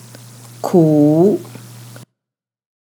クー